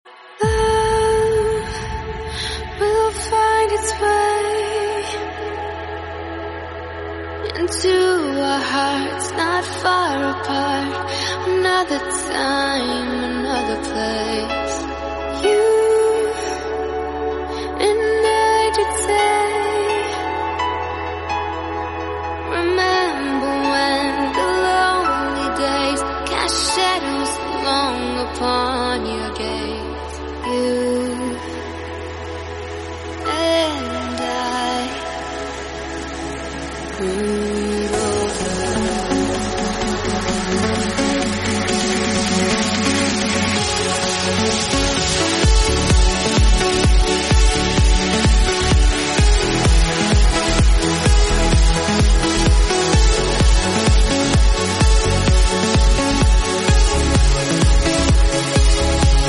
Si el amor tuviera un sonido, seguro sería Vocal Trance.